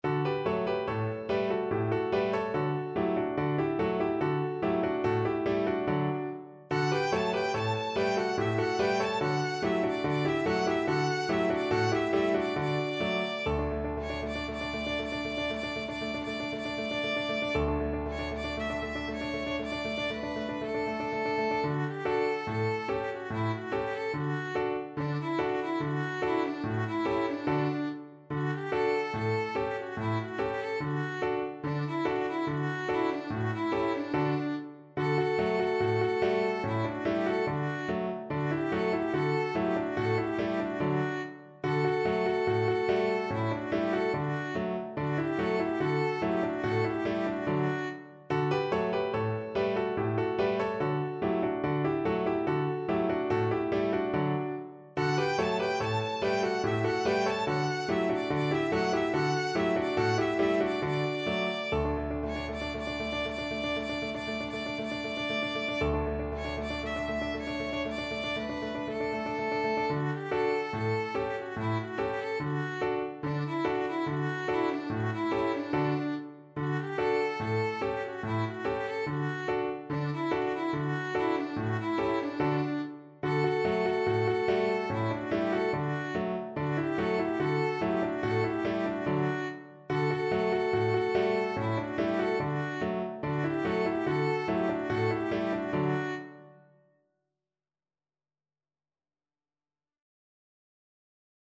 Violin
D major (Sounding Pitch) (View more D major Music for Violin )
4/4 (View more 4/4 Music)
Cheerfully =c.72
World (View more World Violin Music)